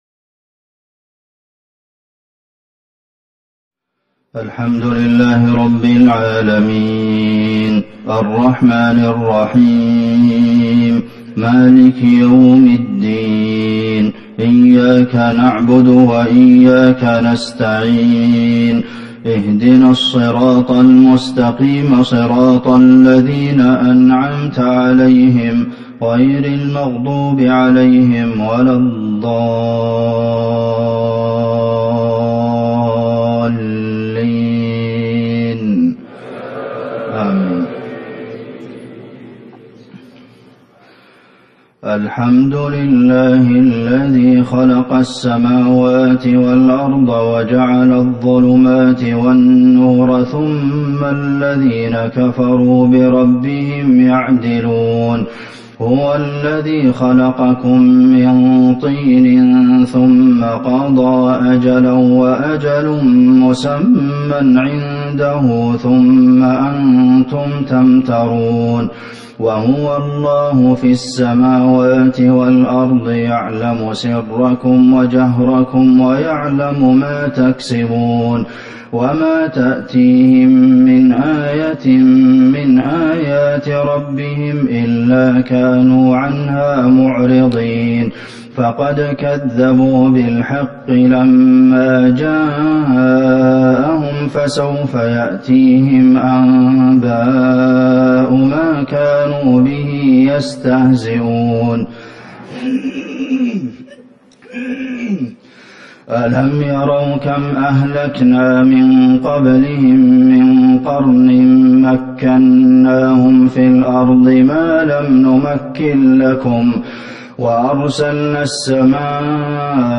صلاة الفجر 7 جمادى الآخرة 1441 من سورة الأنعام | Fajr prayer 1-2-2020 from Surat Al-An’am > 1441 🕌 > الفروض - تلاوات الحرمين